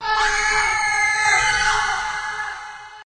Screaming Group Scream